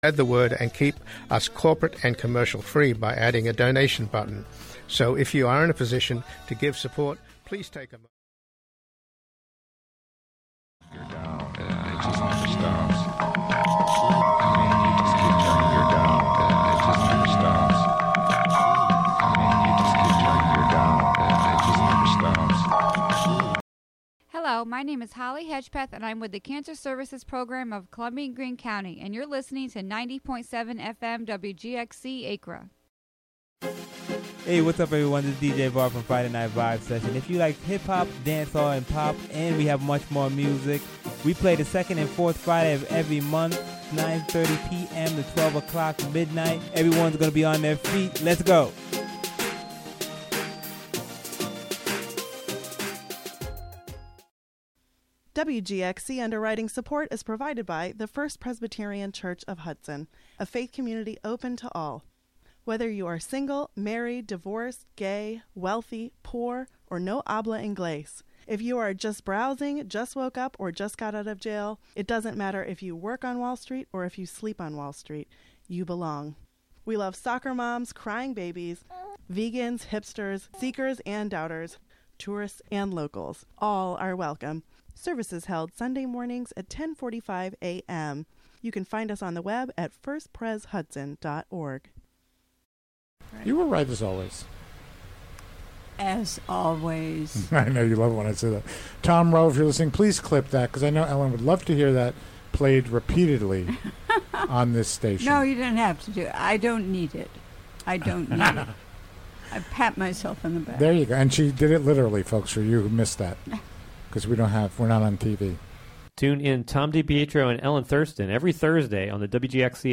"All Together Now!" is a daily news show brought to you by WGXC-FM in Greene and Columbia counties.
"All Together Now!" features local and regional news, weather updates, feature segments, and newsmaker interviews.